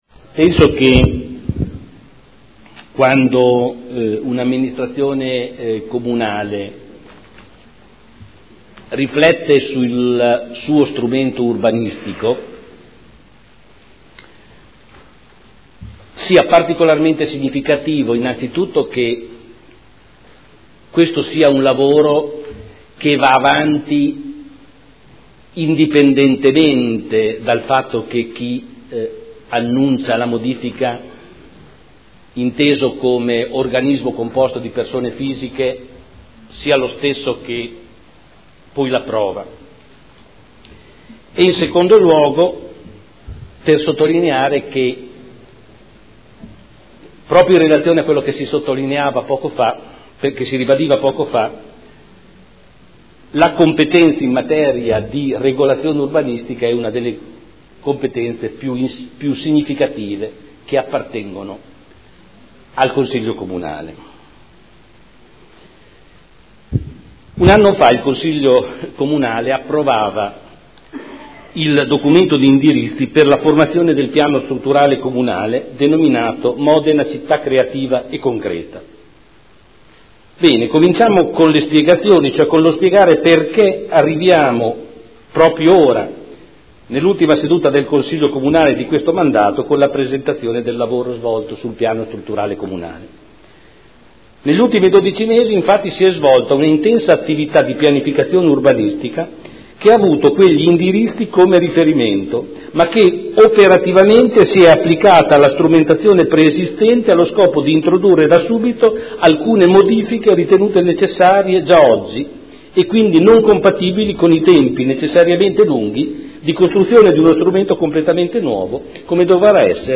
Giorgio Pighi — Sito Audio Consiglio Comunale
Seduta del 7 aprile.Stato di avanzamento del lavoro relativo al nuovo Piano Strutturale Comunale – Comunicazione del Sindaco